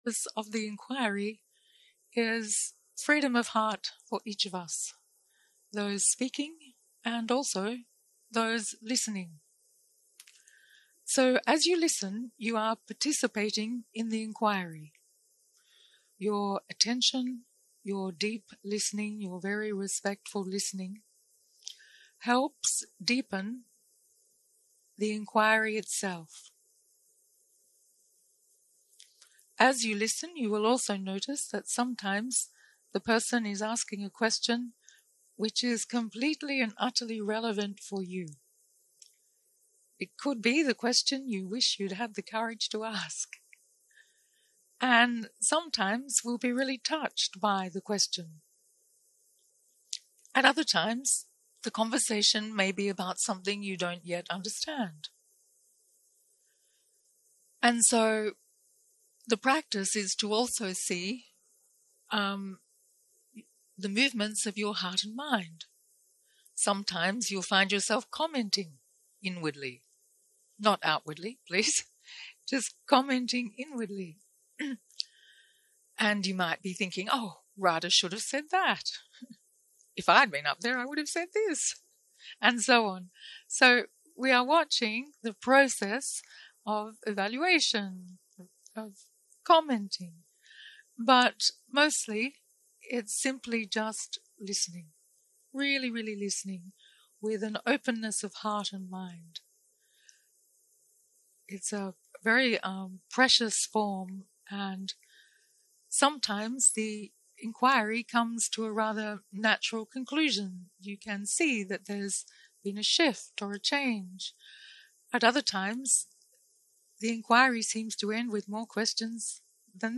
Dharma type: Inquiry